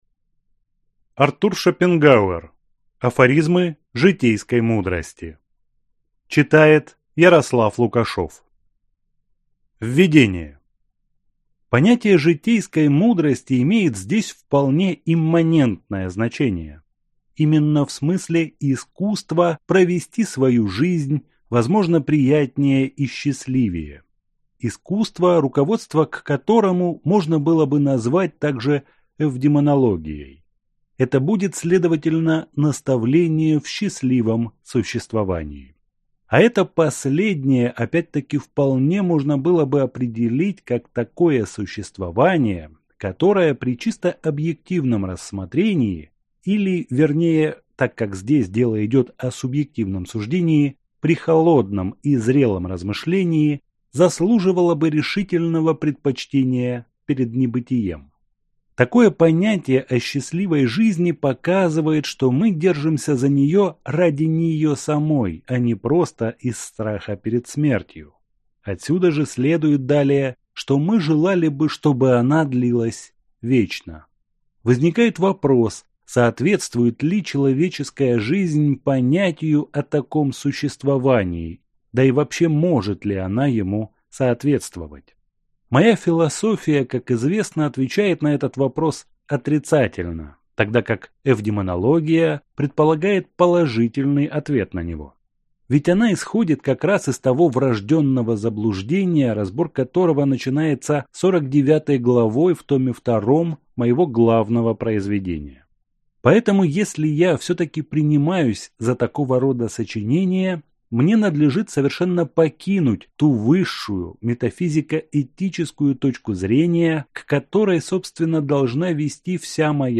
Аудиокнига Афоризмы житейской мудрости | Библиотека аудиокниг
Прослушать и бесплатно скачать фрагмент аудиокниги